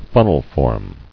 [fun·nel·form]